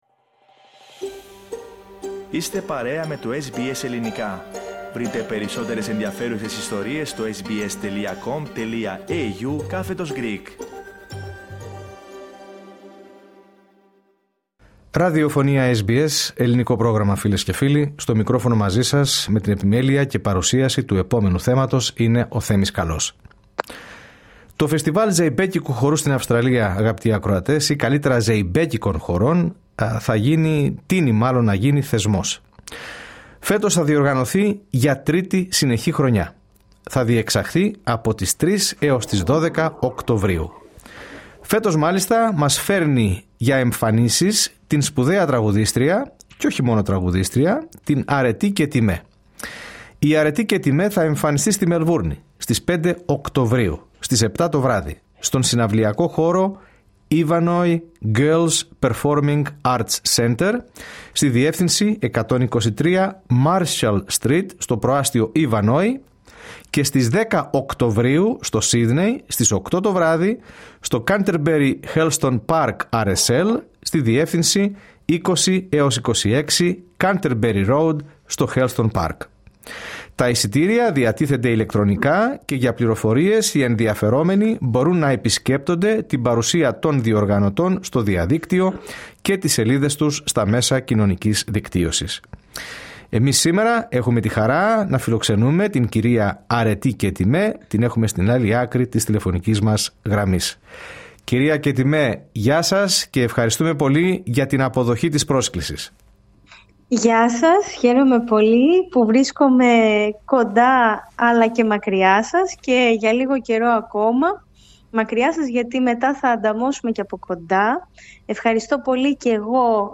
Η Αρετή Κετιμέ παραχώρησε στο Πρόγραμμά μας, SBS Greek, μία εκ βαθέων συνέντευξη που ξεπερνά τη μία ώρα και αναφέρεται στο διάβα της καλλιτεχνικής της πορείας, με τις επιτυχίες αλλά και τις προκλήσεις που κλήθηκε να αντιμετωπίσει αποτελεσματικά. LISTEN TO Αρετή Κετιμέ - Εκ Βαθέων... 1.21'.32'' 1:21:56 Μας μίλησε για το πρόγραμμα που έχει ετοιμάσει, ένα τραγουδιστικό ταξίδι γεμάτο εικόνες και συναισθήματα, με κομμάτια και επιλογές που έχουν χαραχθεί στη διαδρομή και που απευθύνονται σε κάθε γενιά.